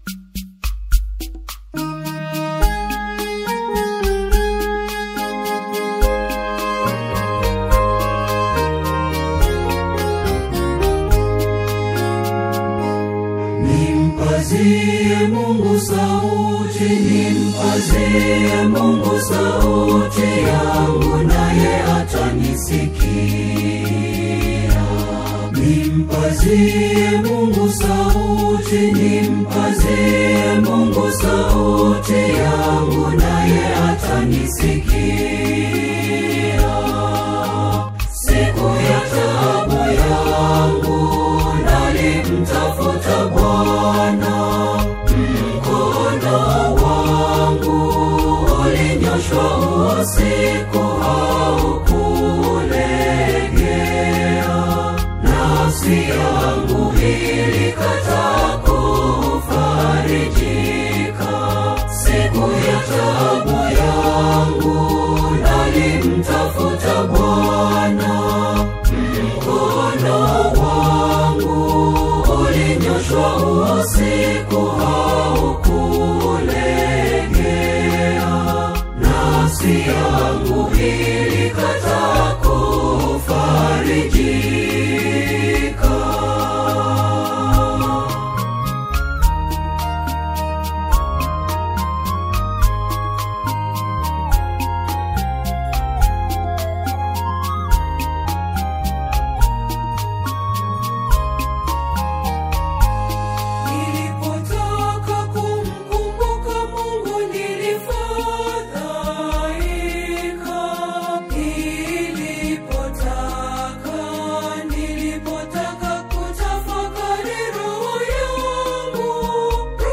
sacred choral music